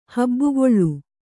♪ habbugoḷḷu